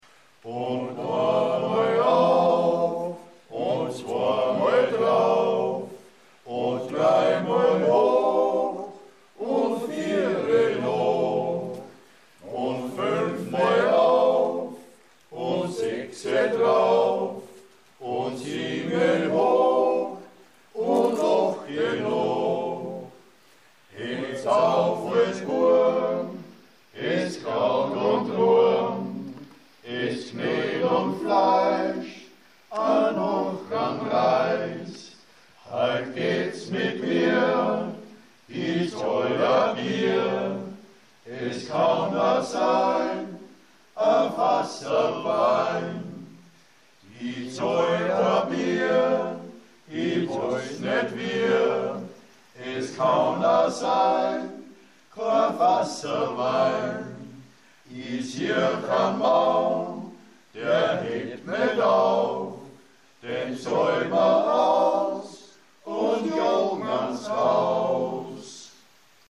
(Texte und Gesangsproben).
Und oanmal auf (Liedertafel Gusswerk)